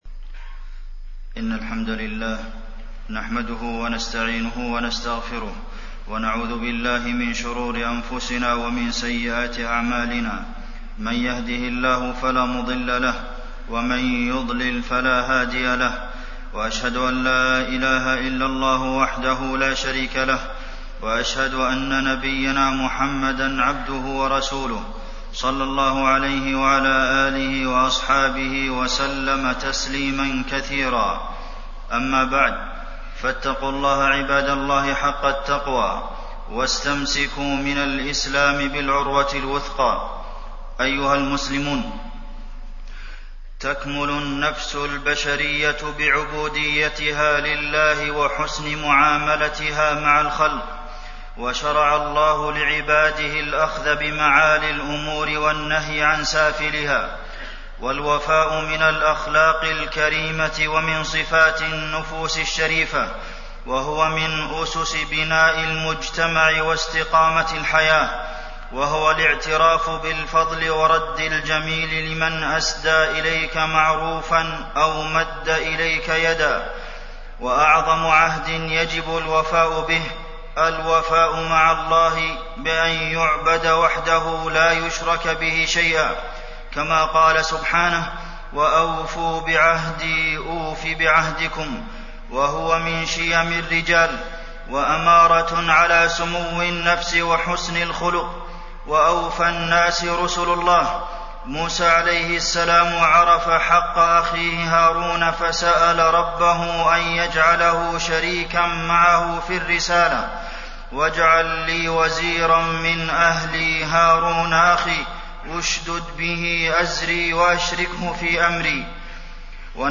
تاريخ النشر ٢٥ محرم ١٤٣٢ هـ المكان: المسجد النبوي الشيخ: فضيلة الشيخ د. عبدالمحسن بن محمد القاسم فضيلة الشيخ د. عبدالمحسن بن محمد القاسم أهمية الوفاء بالعهد